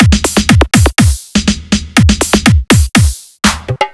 122 BPM Beat Loops Download